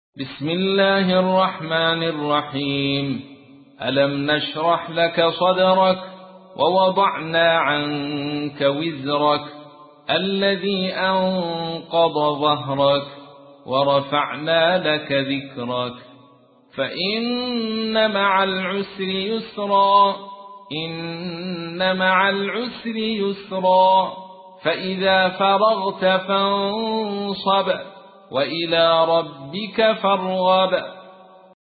تحميل : 94. سورة الشرح / القارئ عبد الرشيد صوفي / القرآن الكريم / موقع يا حسين